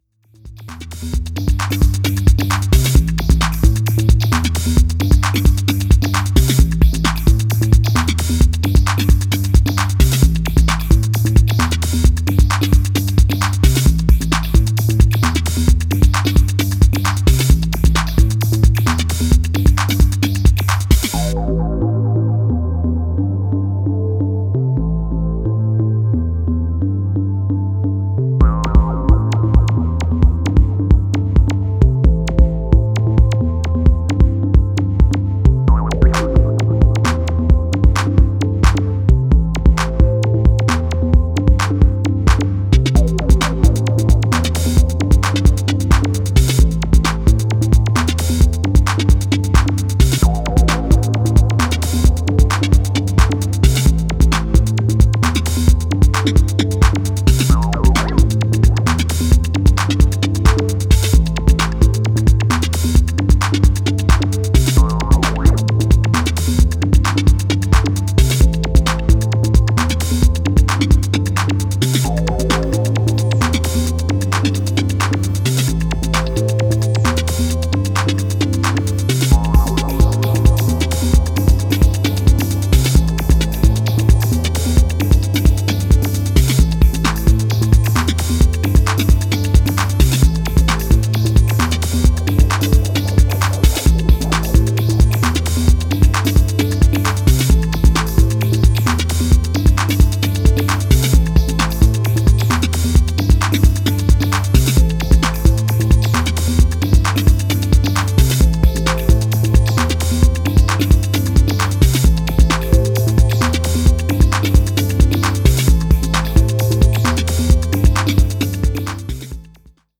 House Techno